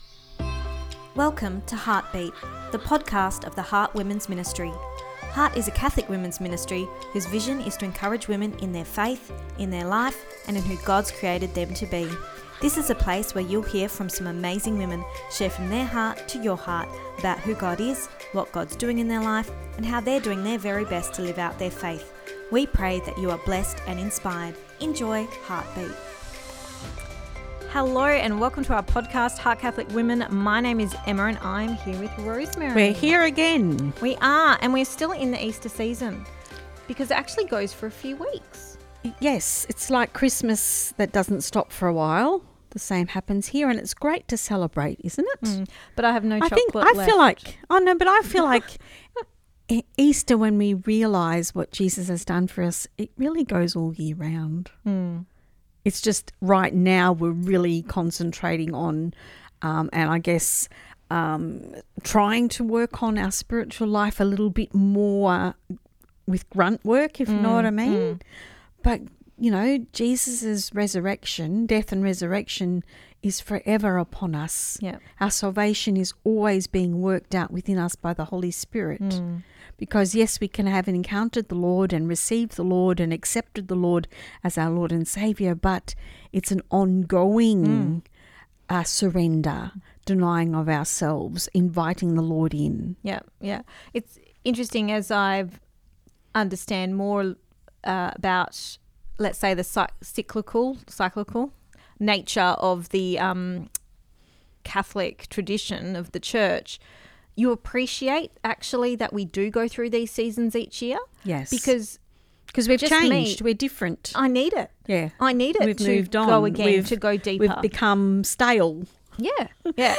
Ep244 Pt2 (Our Chat) – How Do We Say Yes?